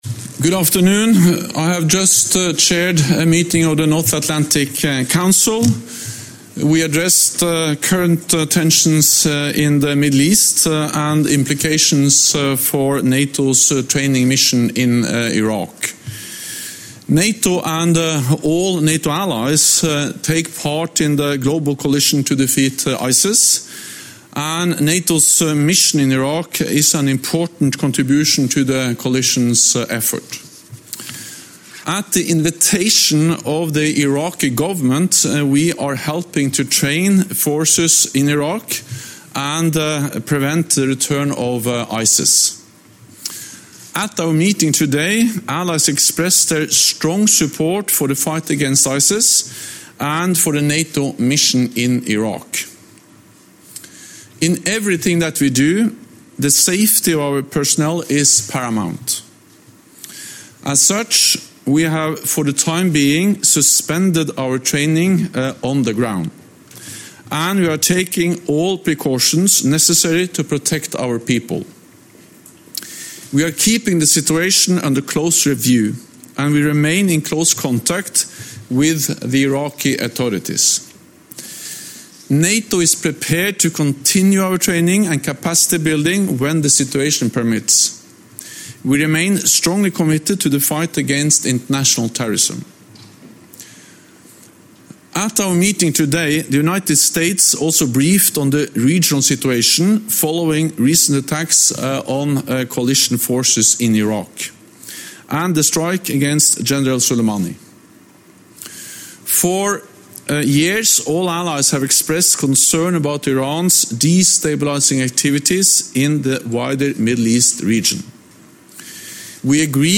Jens Stoltenberg - Press Briefing After North Atlantic Council Meeting on Recent Middle East Tensions (text-audio-video)